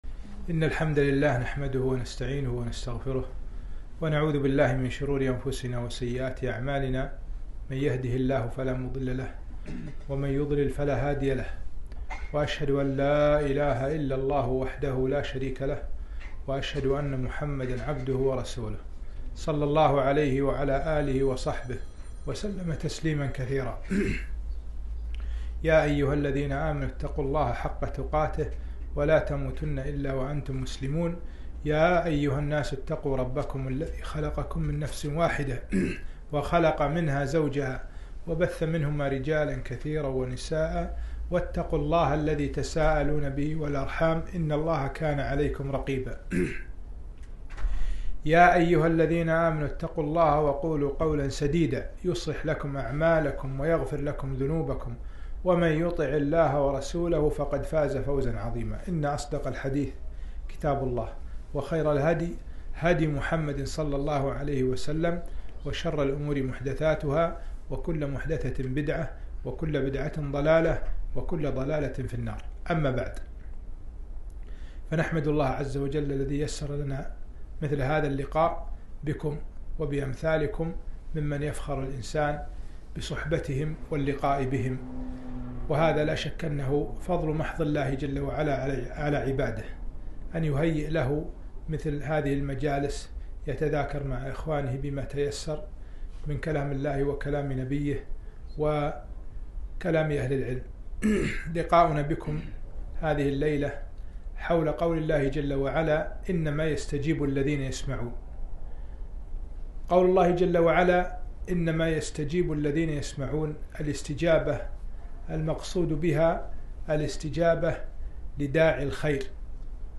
محاضرة - ( إنما يستجيب الذين يسمعون )